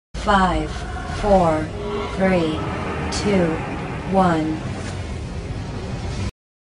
countdown_start.mp3